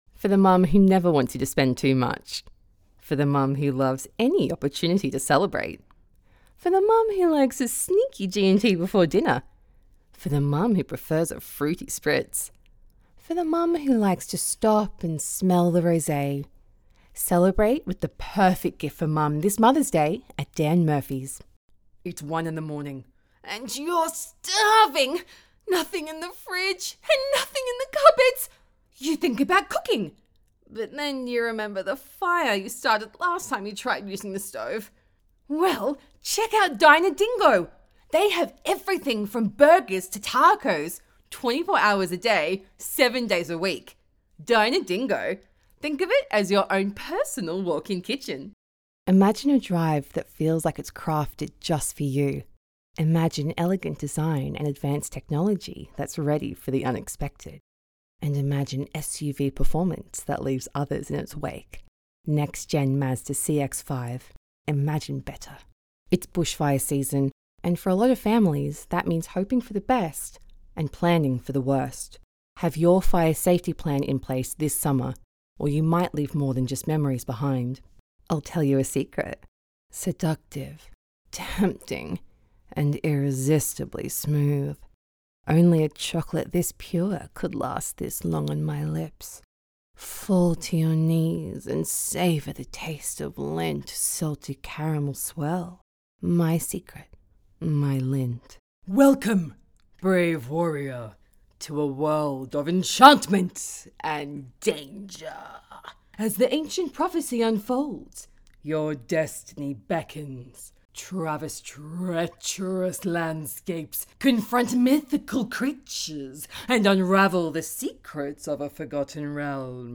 0115Australian_Voice_Reel.mp3